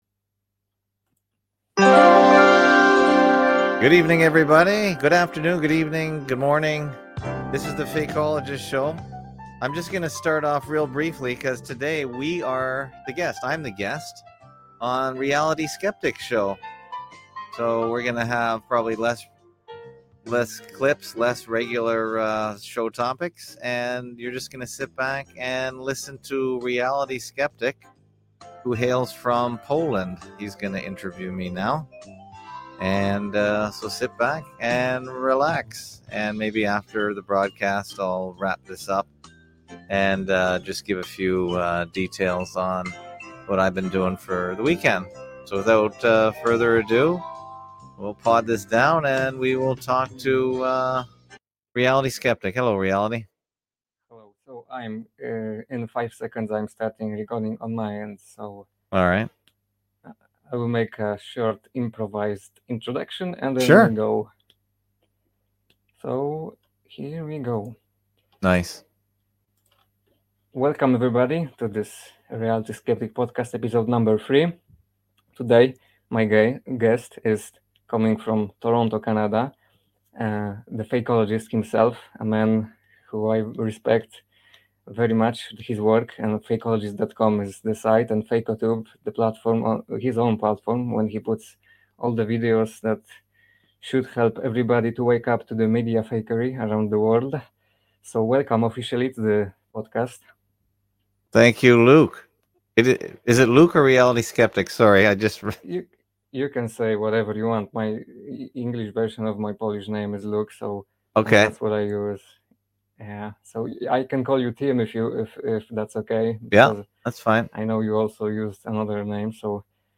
Category: Live Stream